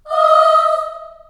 Index of /90_sSampleCDs/USB Soundscan vol.28 - Choir Acoustic & Synth [AKAI] 1CD/Partition A/03-CHILD AHS
D#4 CHS AH-R.wav